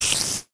spider3.ogg